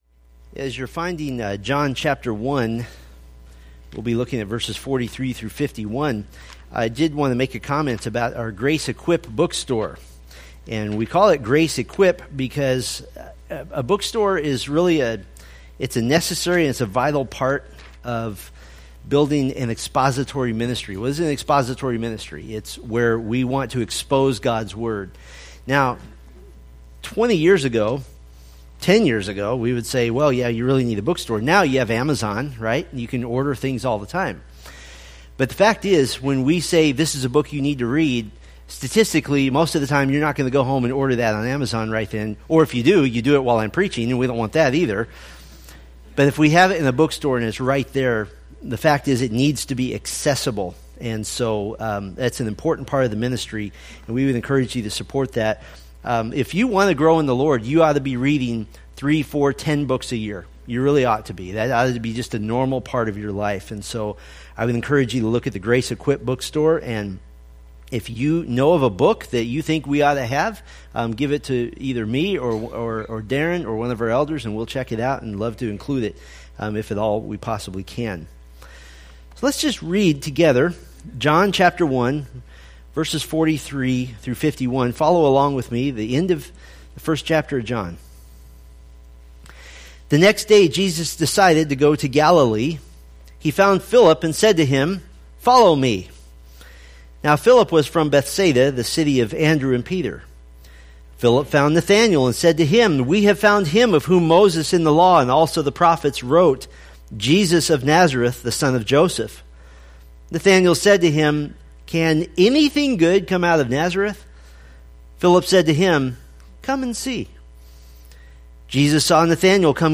From the Pre-Flight Checklist sermon series.